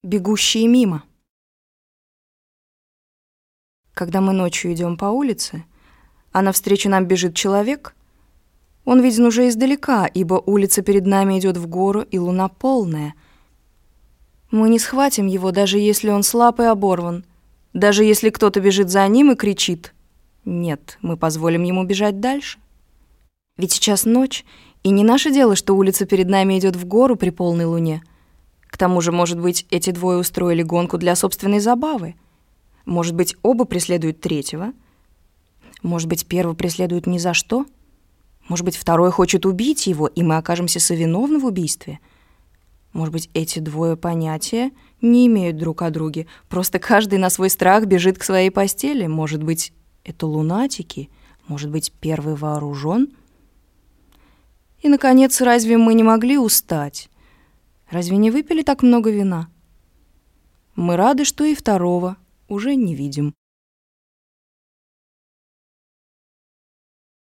Аудиокнига Бегущие мимо. Рассказы | Библиотека аудиокниг